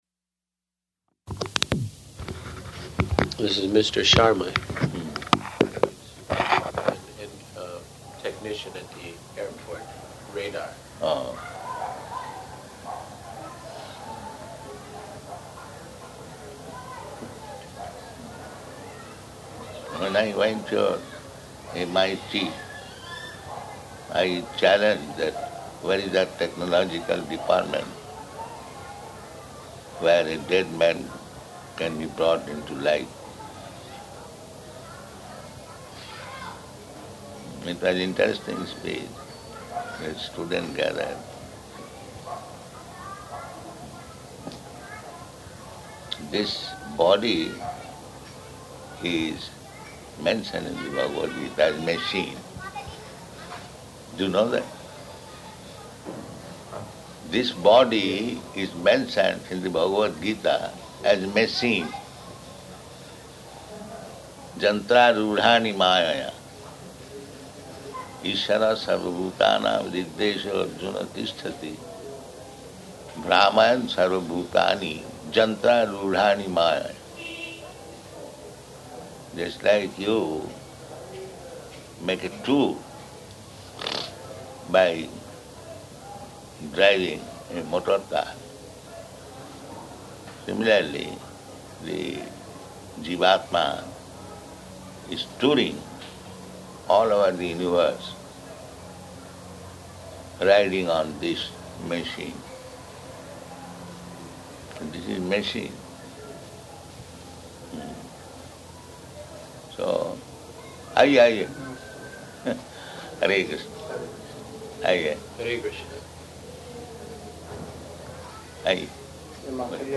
Type: Conversation
Location: Bombay